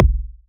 edm-kick-81.wav